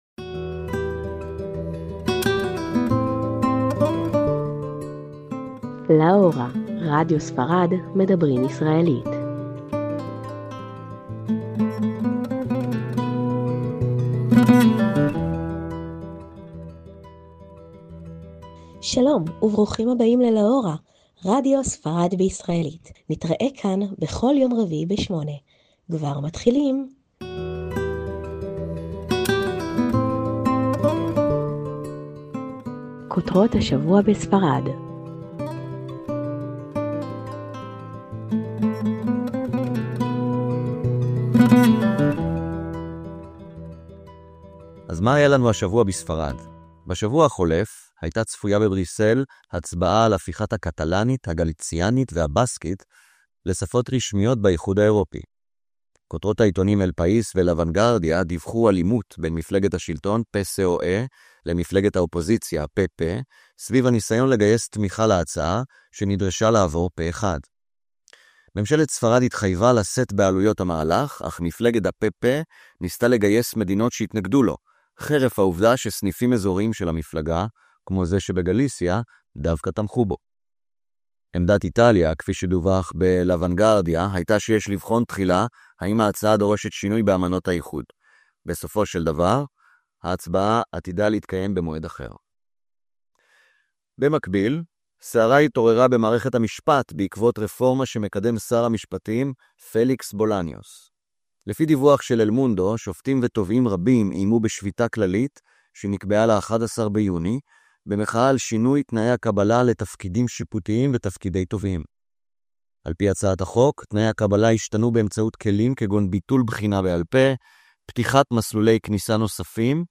ראיון מרתק!